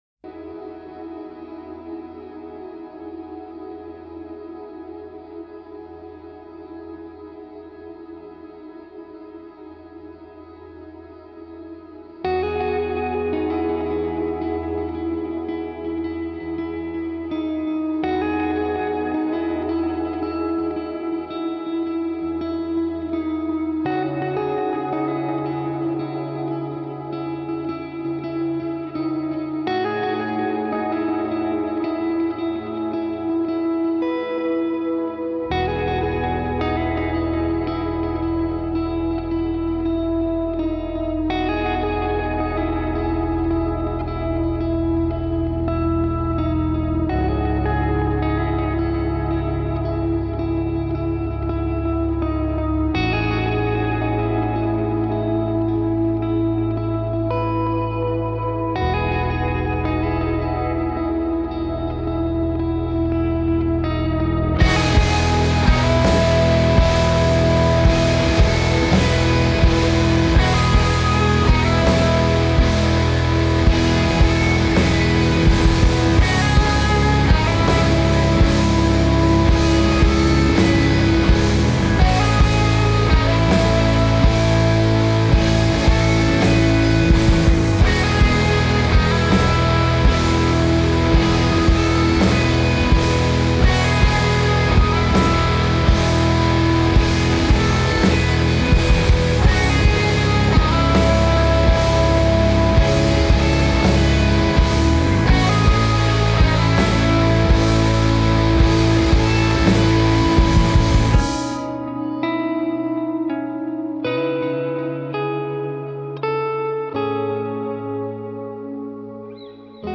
عمیق و تامل برانگیز
گیتار الکترونیک